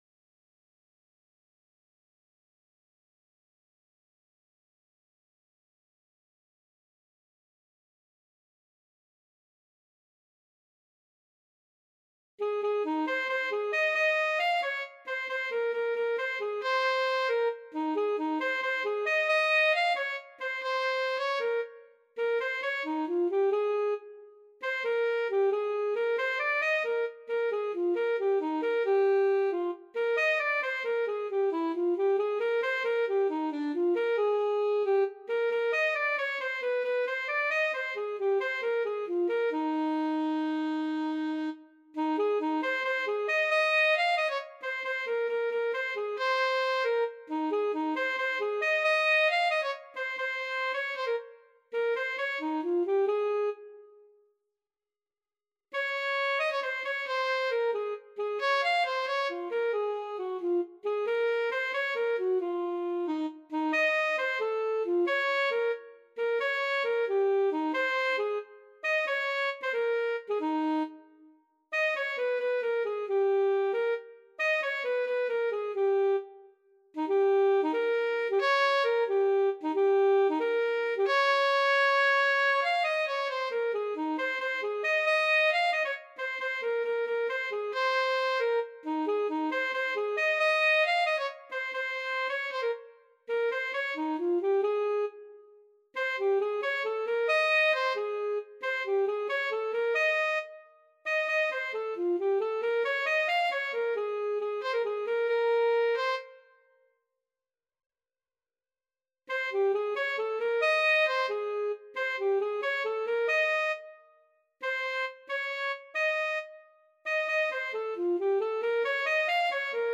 Classical Mozart, Wolfgang Amadeus E amore un ladroncello from Cosi fan tutte Alto Saxophone version
6/8 (View more 6/8 Music)
. = 90 Allegretto vivace
Classical (View more Classical Saxophone Music)
cosi_amore_ladroncello_ASAX.mp3